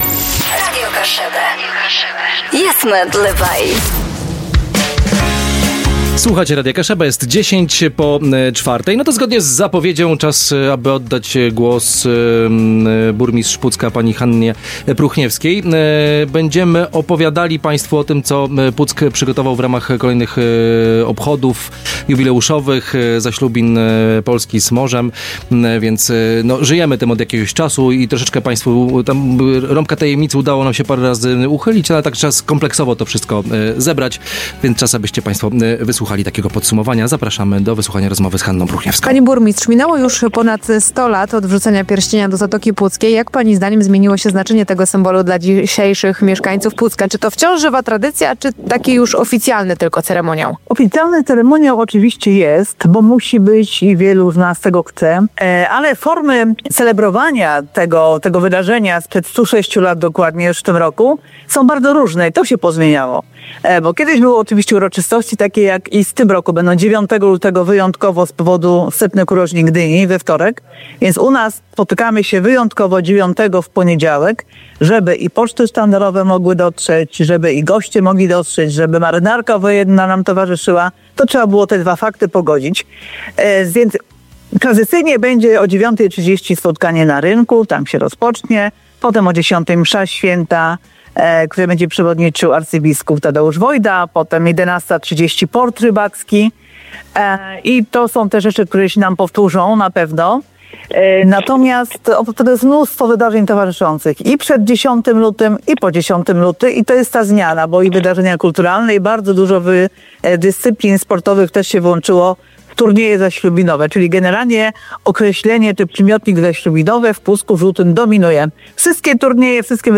O tym, dlaczego Puck był pierwszym oknem na świat odrodzonej Rzeczypospolitej i jak dzisiejsi mieszkańcy pielęgnują pamięć o generale Hallerze, opowiedziała na antenie Radia Kaszëbë burmistrz miasta, Hanna Pruchniewska.